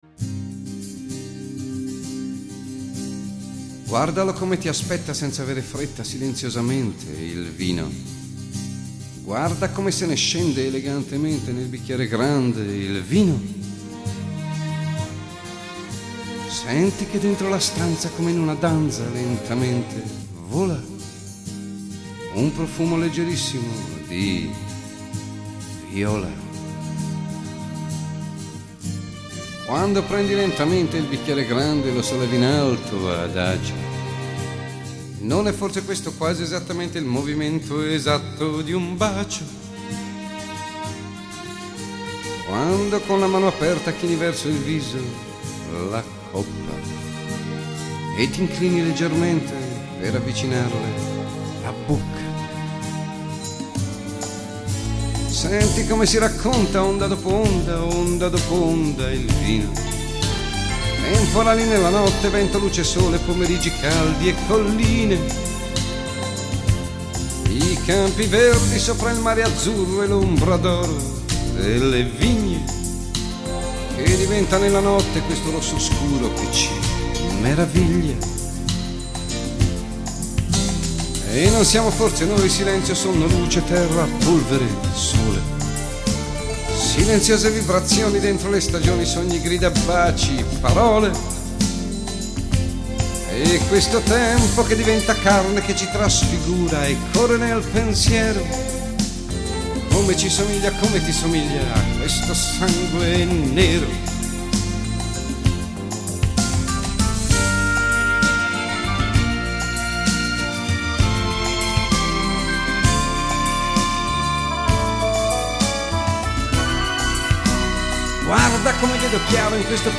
Incontro con il cantautore, attore, regista e scrittore